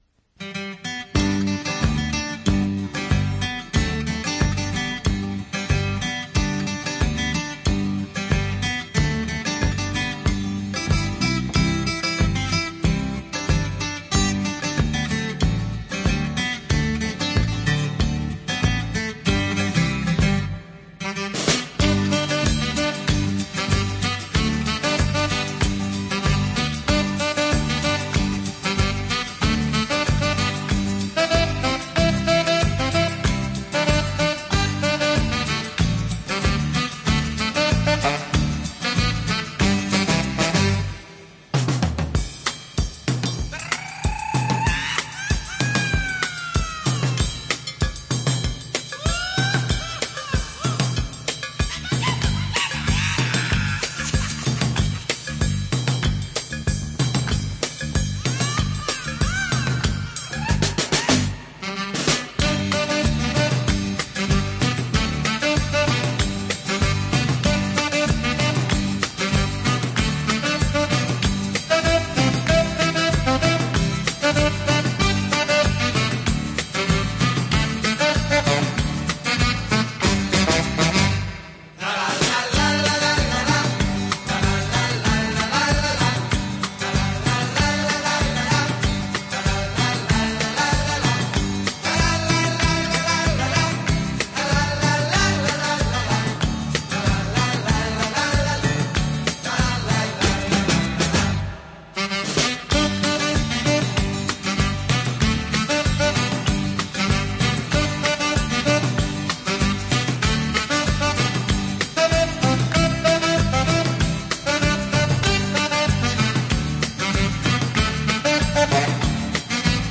Жанр: Easy Listening, Sax